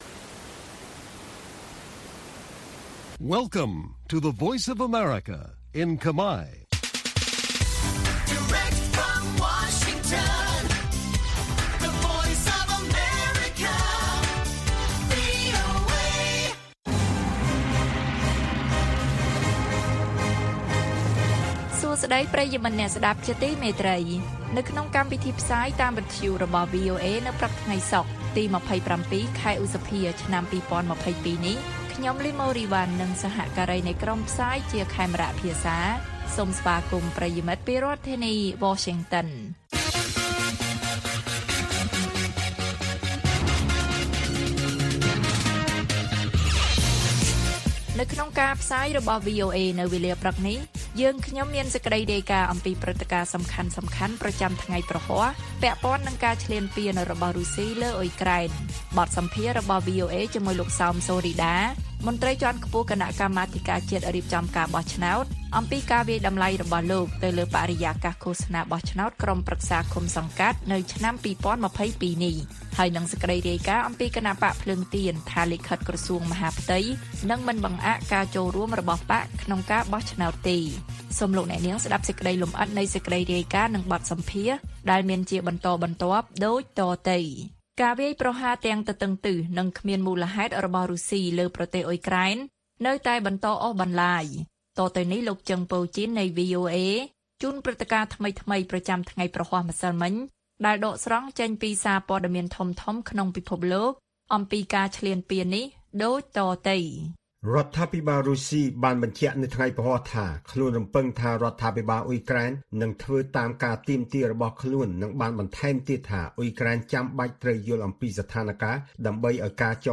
ព័ត៌មាននៅថ្ងៃនេះមាន ព្រឹត្តិការណ៍សំខាន់ៗប្រចាំថ្ងៃព្រហស្បតិ៍ ពាក់ព័ន្ធនឹងការឈ្លានពានរបស់រុស្ស៊ីលើអ៊ុយក្រែន។ បទសម្ភាសន៍ VOA៖ គ.ជ.ប.ថា បរិយាកាសឃោសនាបោះឆ្នោតល្អជាងកាលពីឆ្នាំ២០១៧។ គណបក្សភ្លើងទៀនថា លិខិតក្រសួងមហាផ្ទៃនឹងមិនបង្អាក់ការចូលរួមរបស់បក្សក្នុងការបោះឆ្នោតទេ៕